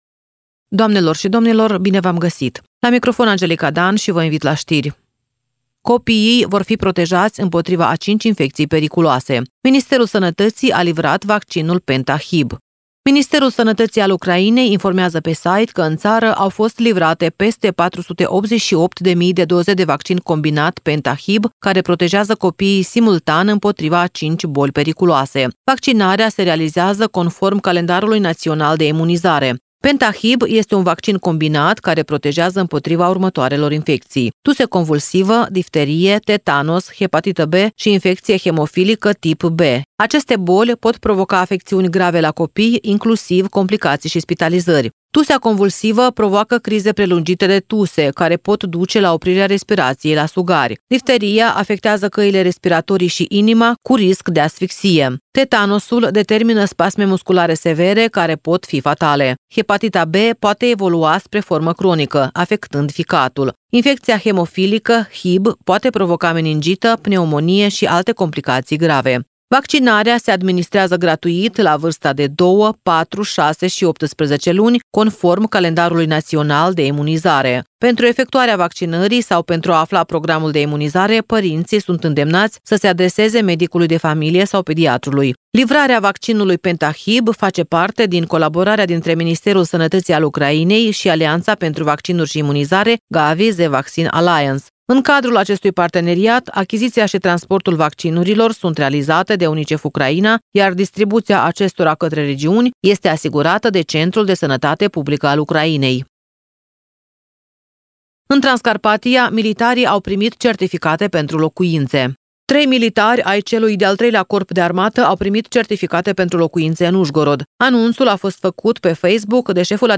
Ştiri Radio Ujgorod – 23.01.2026